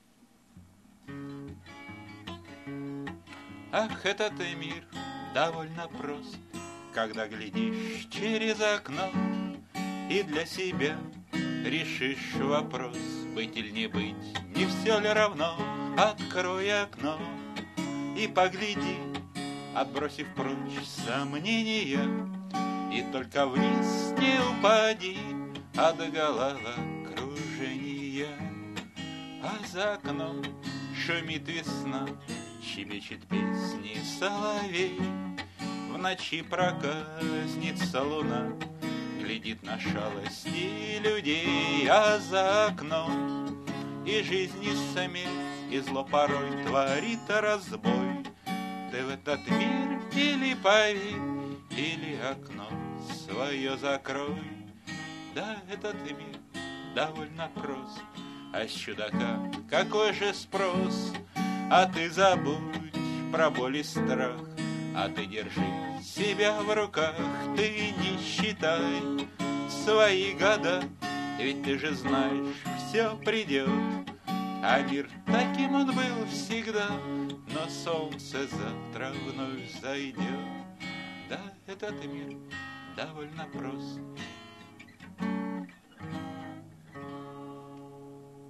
Я не строю иллюзий насчет качества :) Но интернет тем и хорош, что можно просто ... спеть друзьям. Увы, у меня вроде есть слух, но совершенно нет голоса. Так что можно считать, что это просто песня для друзей у костра. Я не стал делать никаких аранжировок - хотя на компьютере это просто.